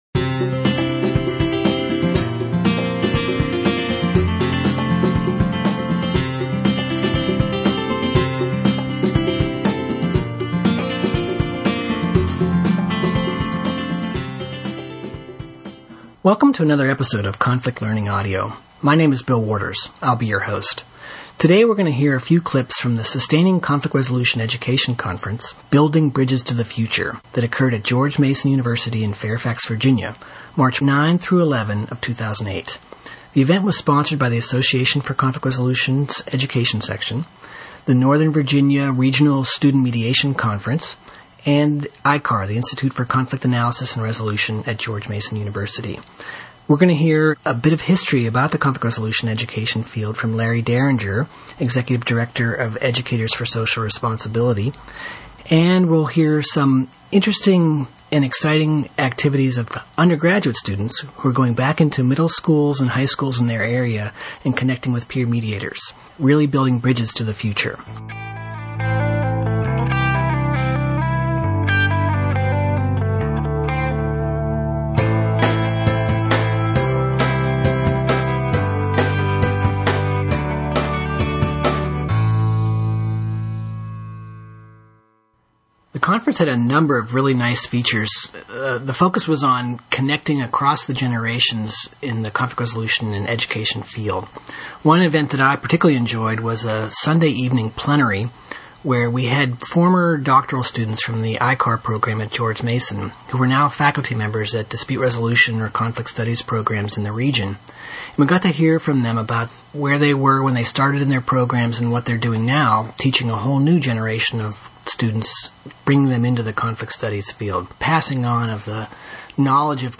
In this episode we hear highlights from the ACR Education Section conference Sustaining Conflict Resolution Education: Building Bridges to the Future held March 9-11, 2008 in Fairfax, Virginia on the campus of George Mason University.